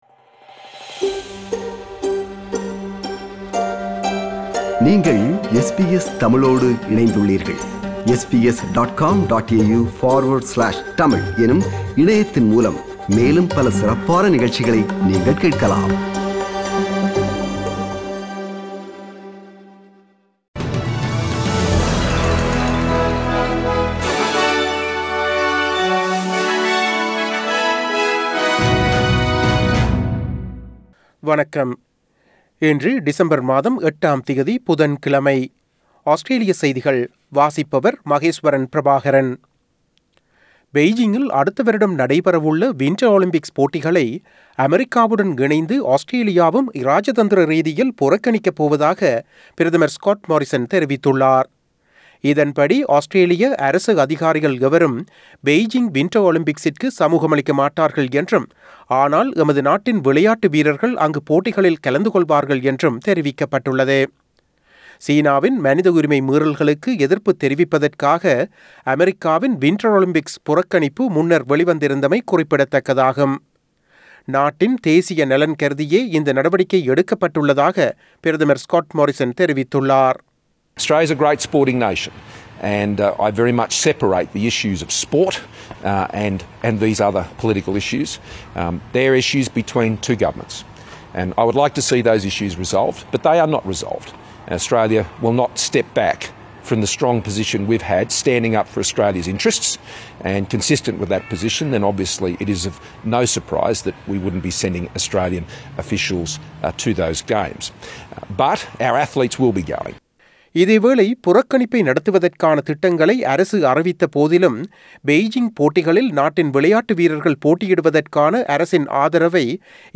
Australian news bulletin for Wednesday 08 December 2021.